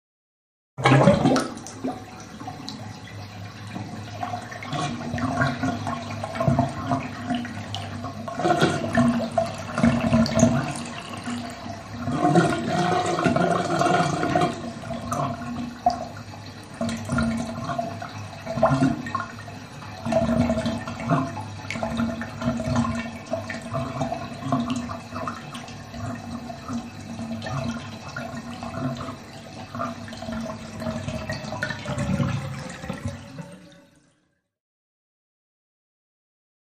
Bathtub; Drain; Bathtub Draining With Lots Of Gurgling Throughout. Close Perspective.